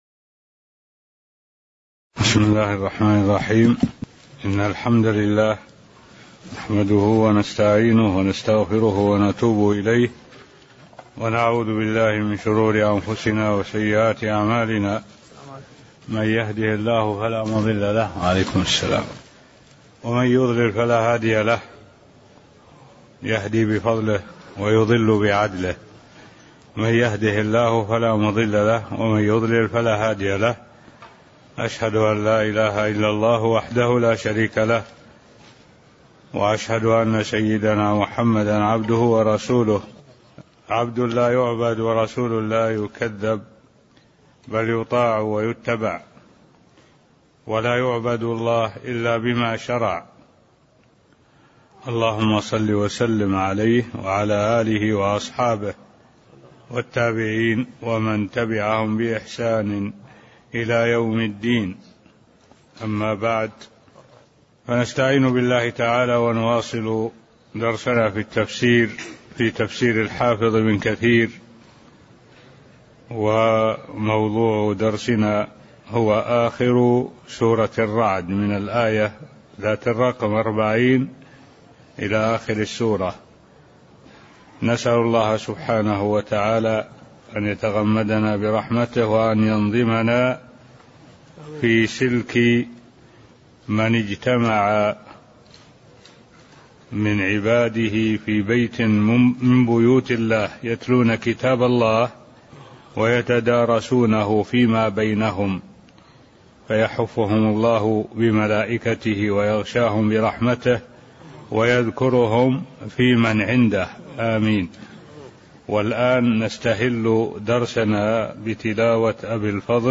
المكان: المسجد النبوي الشيخ: معالي الشيخ الدكتور صالح بن عبد الله العبود معالي الشيخ الدكتور صالح بن عبد الله العبود من آية رقم 40 - نهاية السورة (0561)